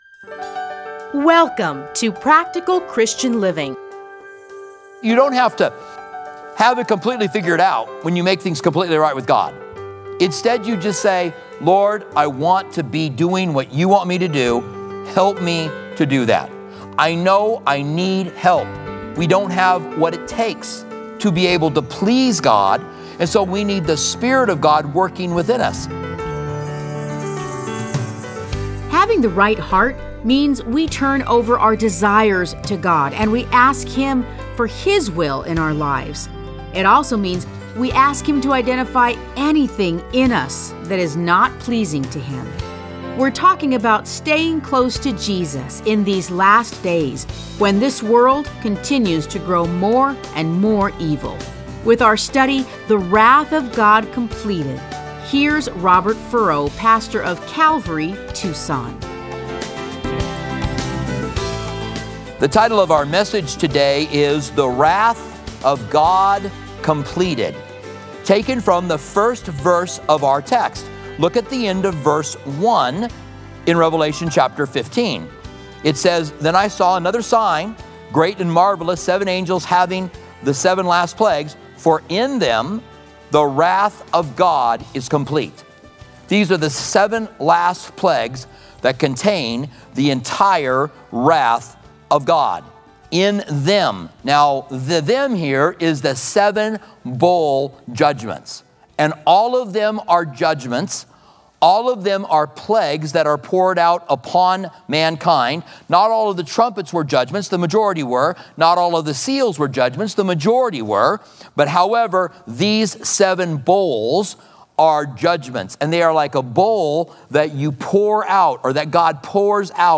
Listen to a teaching from Revelation 15:1-8.